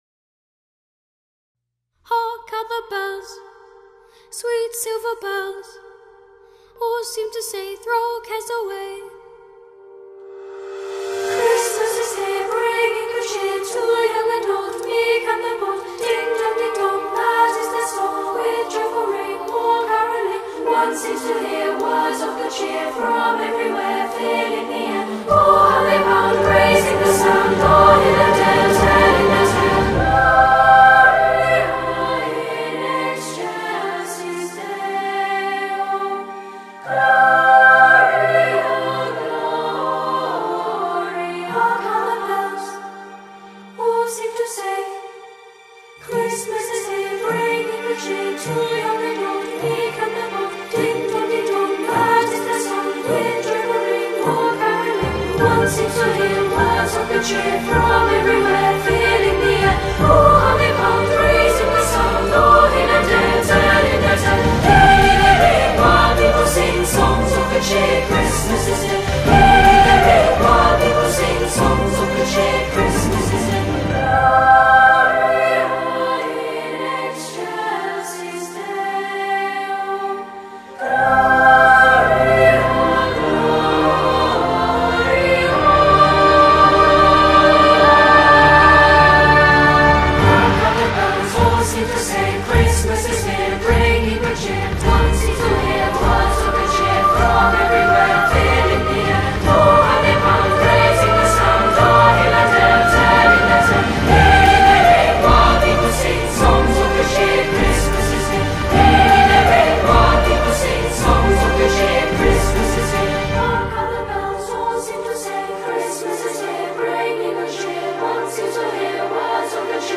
Chant de Noël traditionnel ukrainien